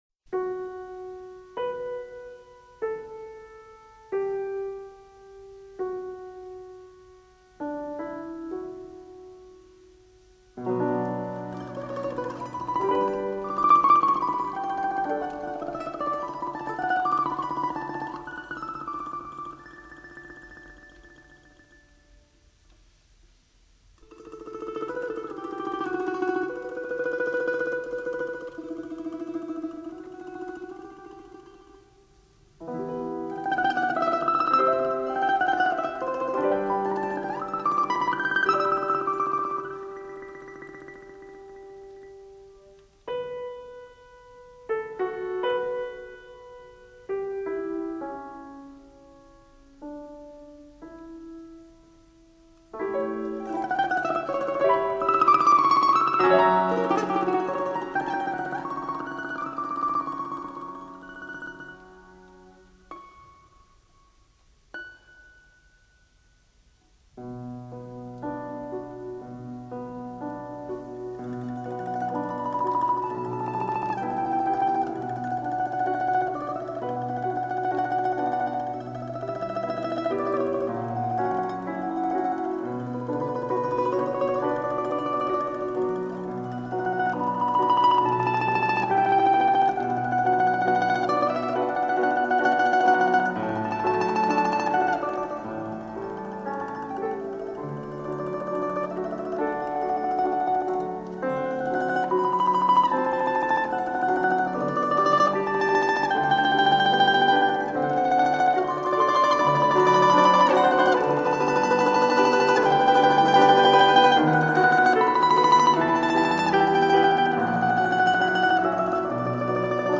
Домра в мелодиях классики